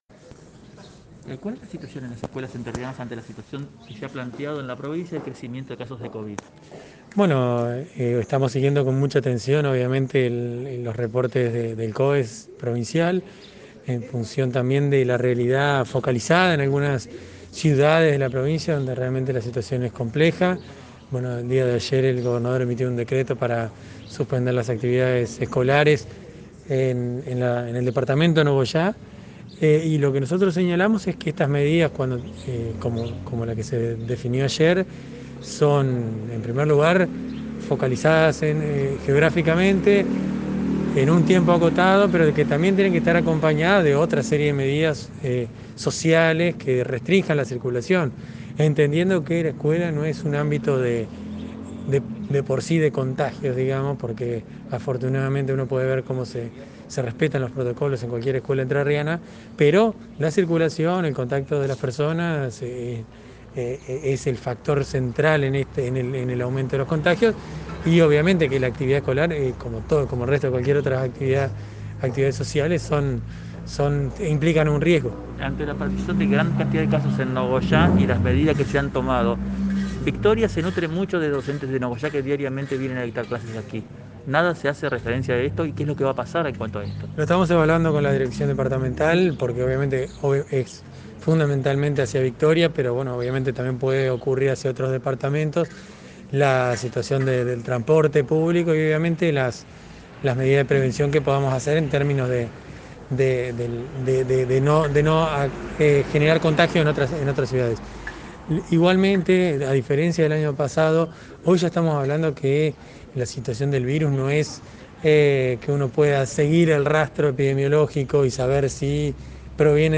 Presidente CGE en Victoria en diálogo con el móvil de LT39 – Lt39 Noticias
Rueda de prensa – Presidente CGE sobre el pedido de apertura de comedores escolares y la problemática áulica